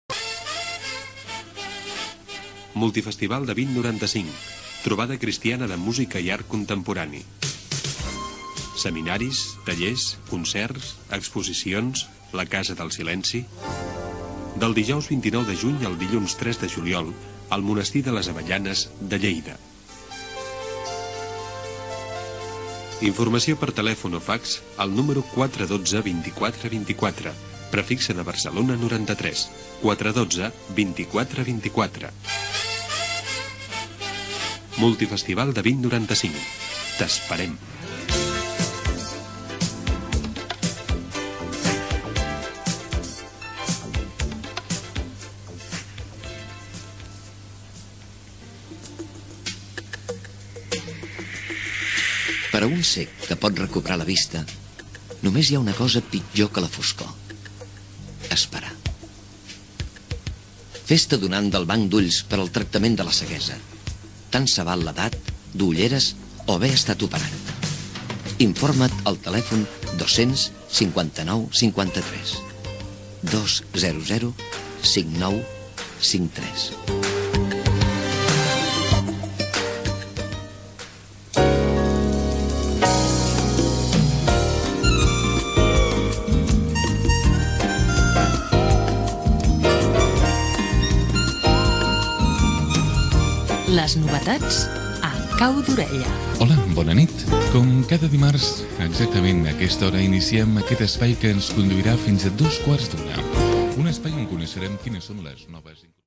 Publicitat (veu Joan Pera), presentació del programa
Entreteniment